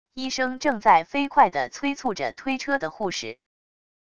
医生正在飞快的催促着推车的护士wav音频